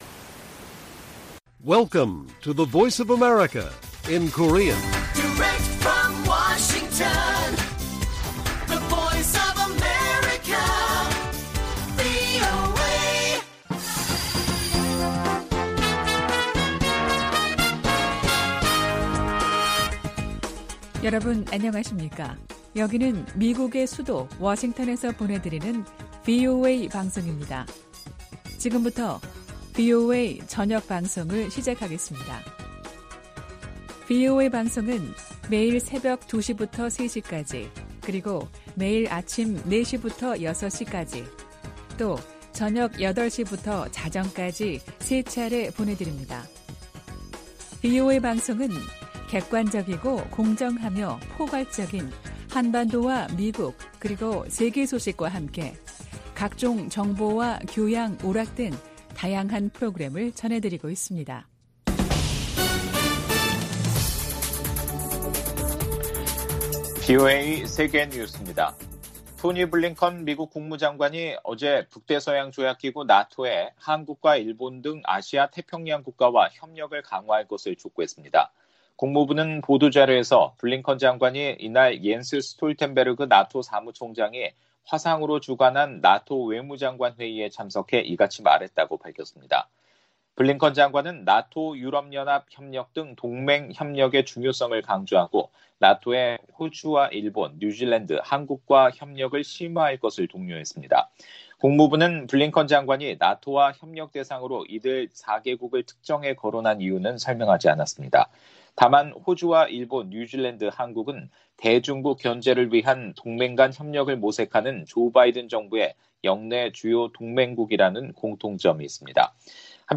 VOA 한국어 간판 뉴스 프로그램 '뉴스 투데이', 1부 방송입니다.